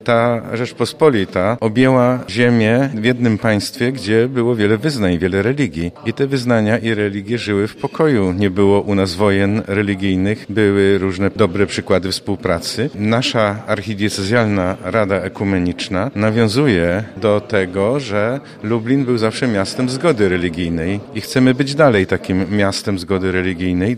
– Mamy rok 450-lecia podpisania Unii Lubelskiej. Tyle lat minęło od tego ważnego dla całej Europy wydarzenia – mówi abp lubelski Stanisław Budzik.